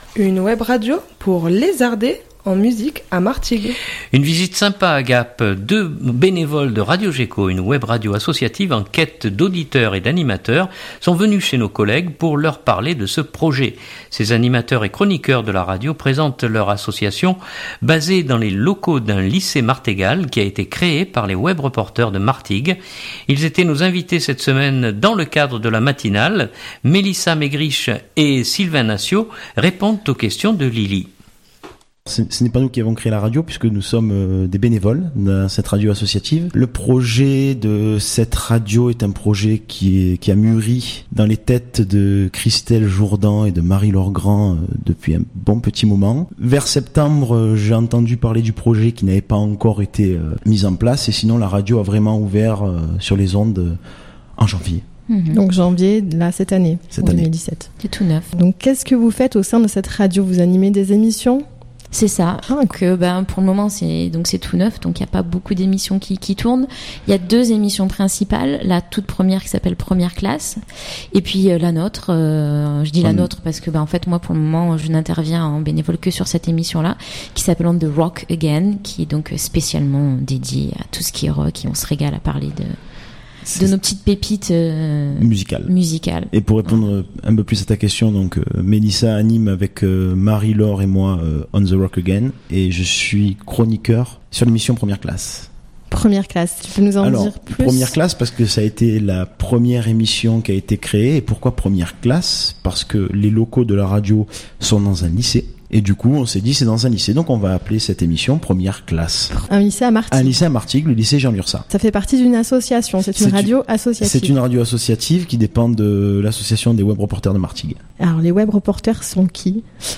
Une visite sympa à Gap : deux bénévoles de Radio Gekko, une webradio associative en quête d’auditeurs et d’animateurs sont venus chez nos collègues pour leur parler de ce projet. Ces animateurs et chroniqueurs de la radio présentent leur association basée dans les locaux d’un lycée martégal qui a été créée par les Webreporters de Martigues.